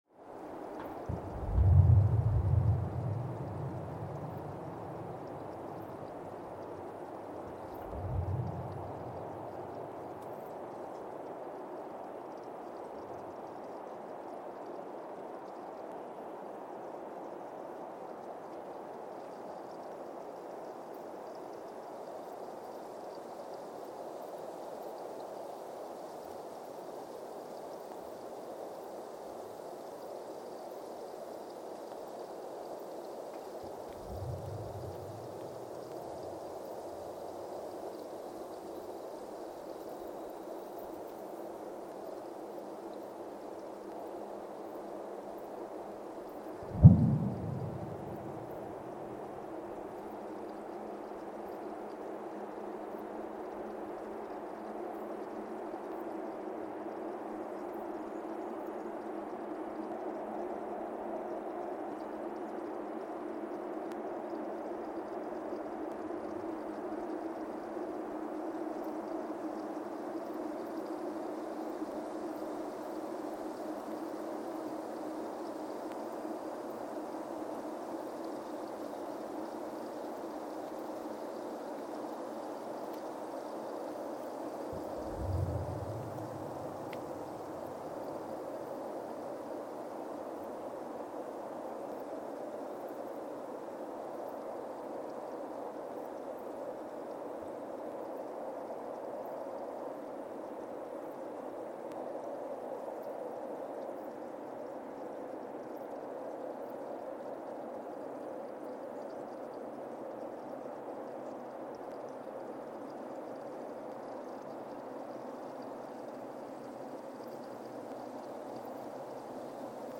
Weston, MA, USA (seismic) archived on December 15, 2024
Sensor : CMG-40T broadband seismometer
Speedup : ×1,800 (transposed up about 11 octaves)
Loop duration (audio) : 05:36 (stereo)